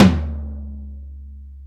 TOM XTOML09R.wav